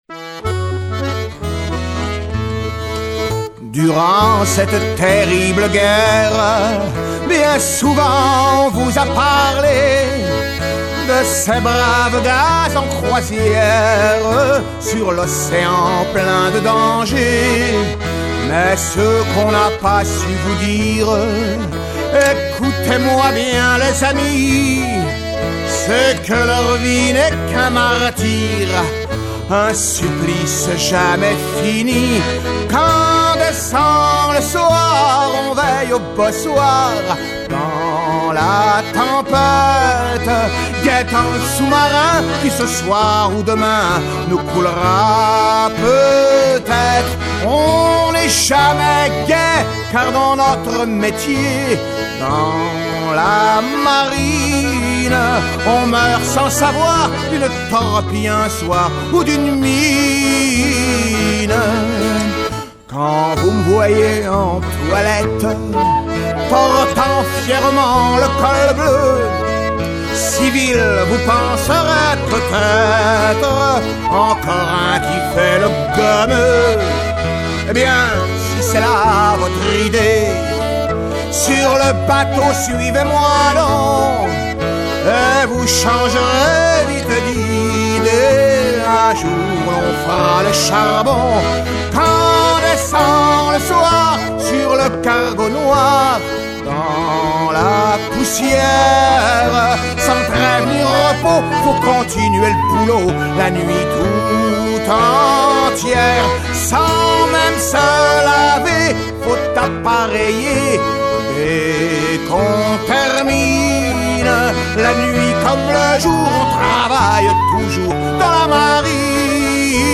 Chanson de la guerre 14-18 interdite dans la flotte